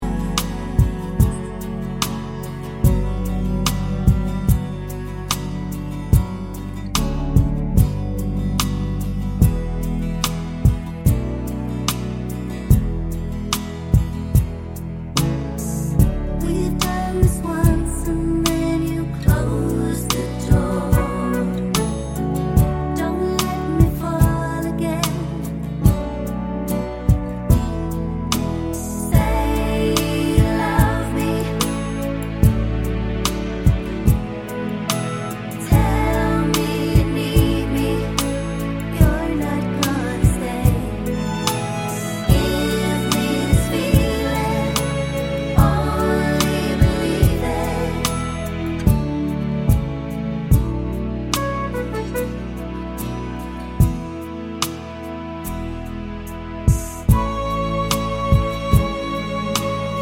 no Backing Vocals Irish 4:34 Buy £1.50